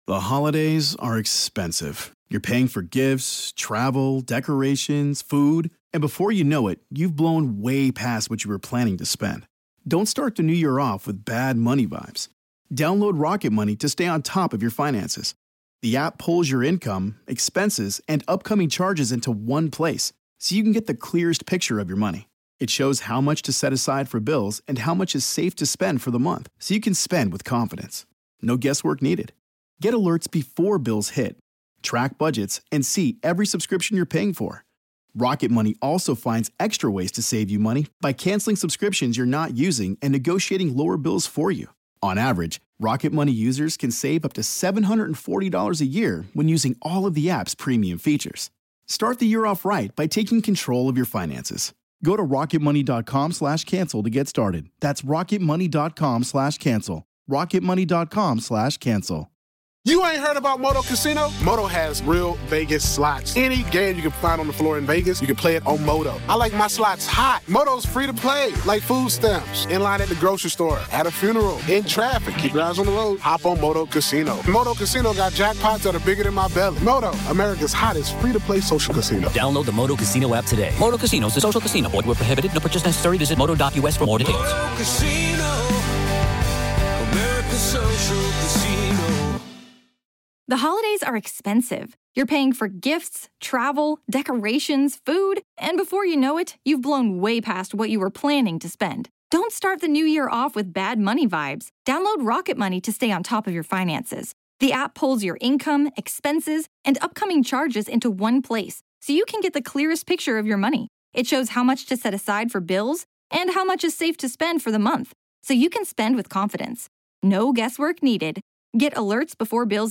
This is Part One of our conversation.